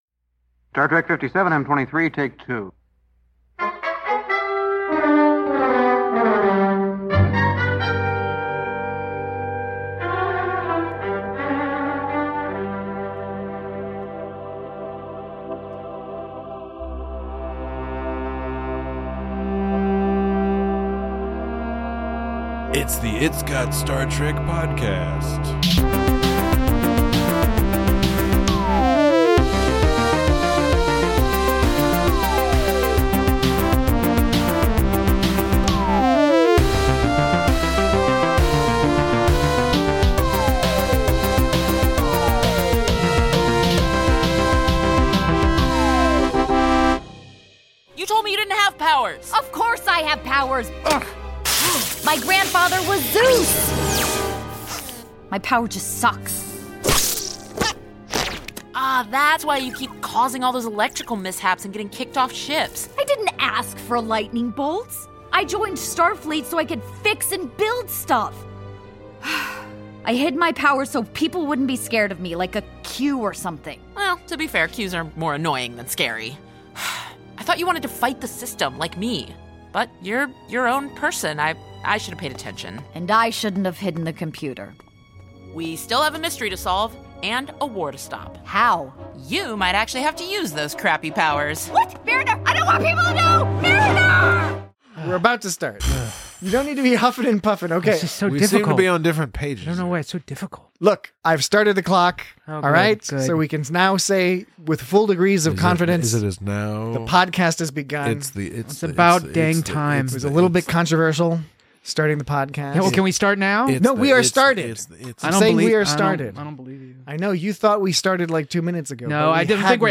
Join your shape-agnostic hosts as they discuss hilarious jokes strapped onto tropey plots, debate the nature and purpose of shapely secretions, and lament the rapidly approaching end to this wonderful addition to the Star Trek franchise.